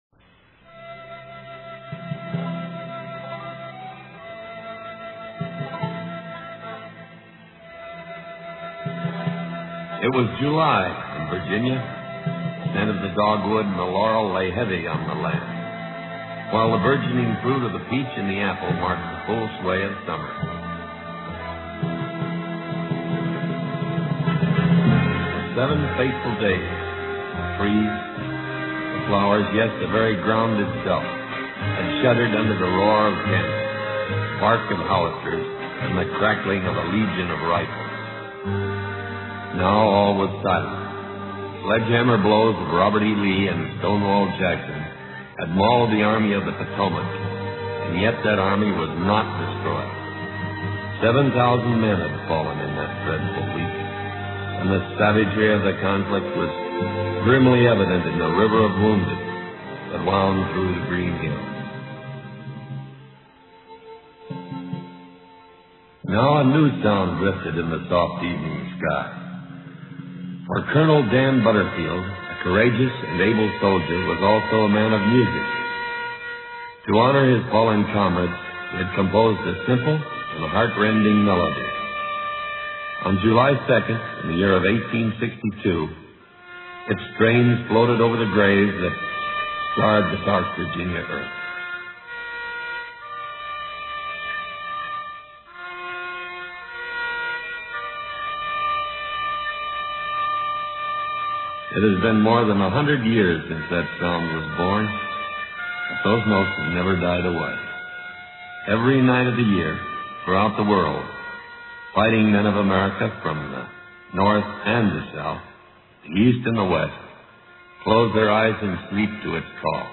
"Taps" is a string of heart rending notes that is played by a lone bugler.
This tune was given words much later and below is a recording that John Wayne did about that Taps melody.